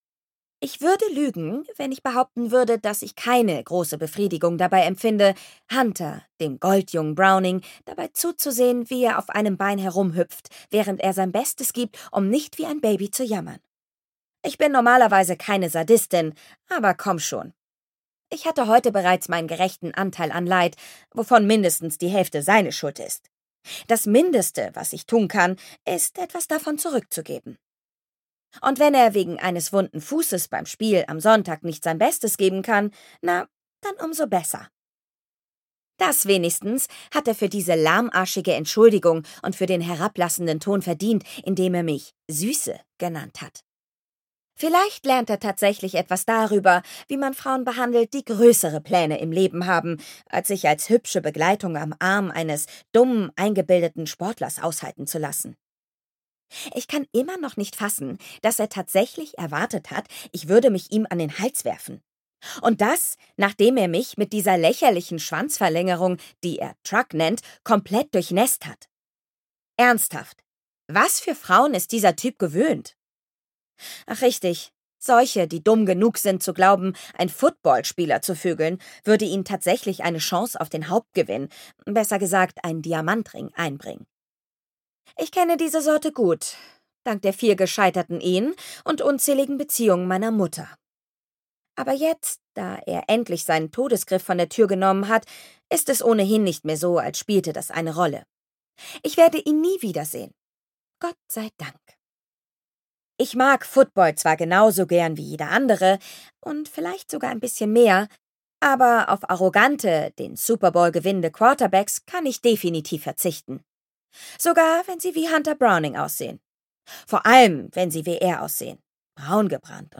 Winning for Love (DE) audiokniha
Ukázka z knihy